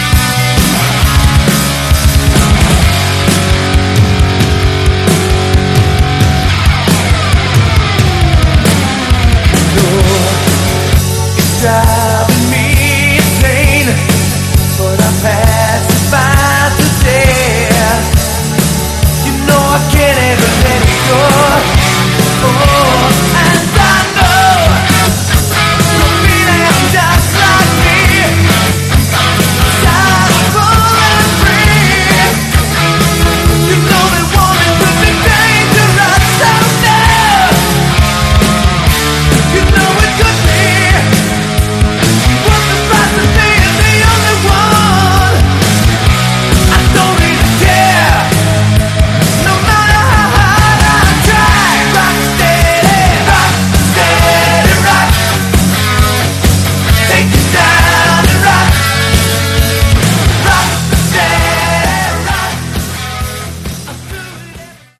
Category: Hard Rock
lead vocals
guitar, keyboards
bass
drums